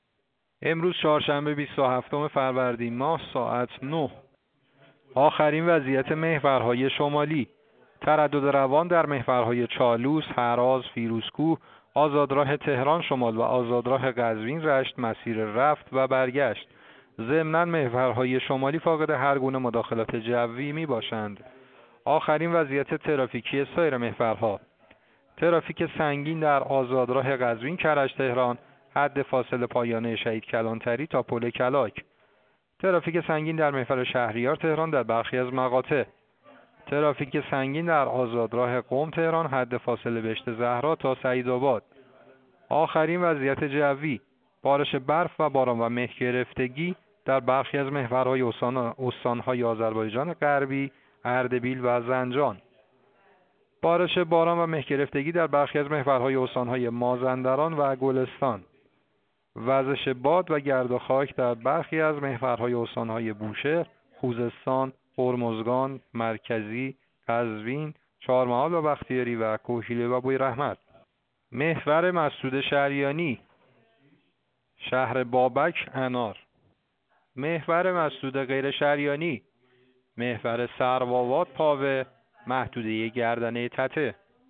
گزارش رادیو اینترنتی از آخرین وضعیت ترافیکی جاده‌ها ساعت ۹ بیست و هفتم فروردین؛